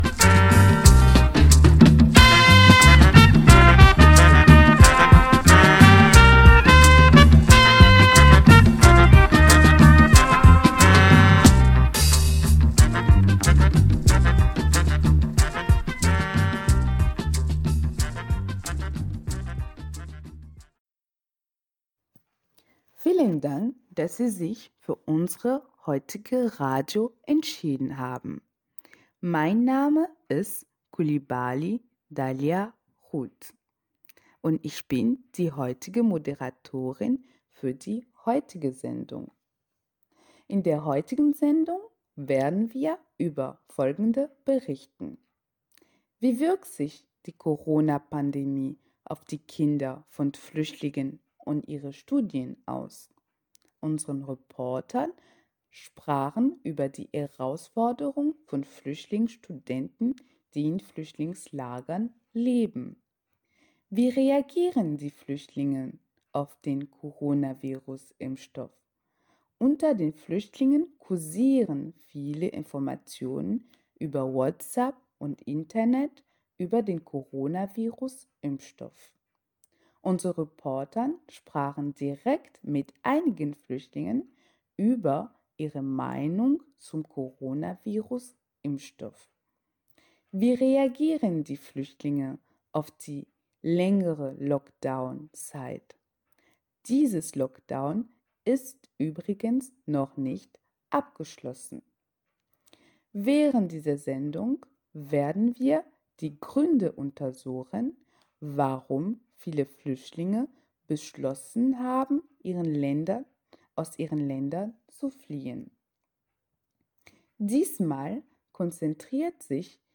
Dieser Ausschnitt der Sendung ist hier zu hören